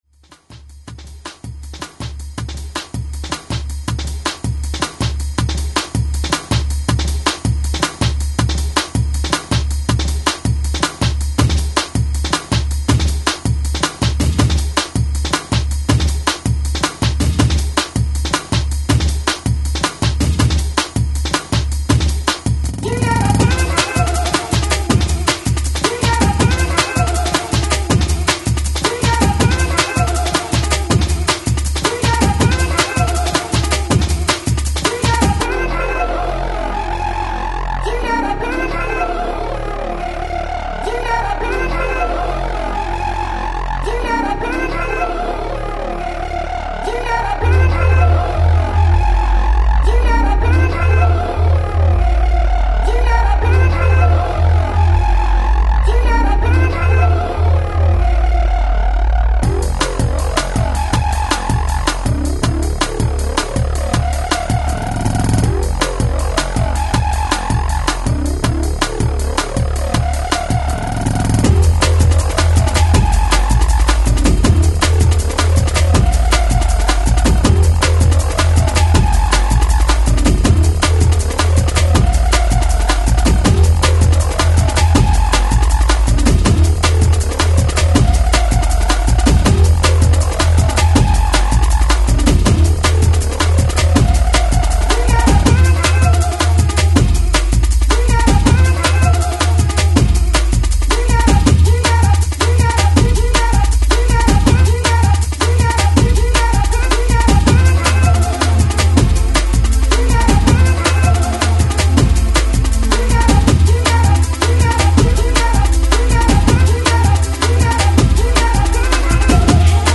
house, hardcore & techno